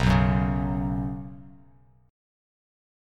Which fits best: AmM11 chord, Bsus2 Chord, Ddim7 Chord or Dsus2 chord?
Bsus2 Chord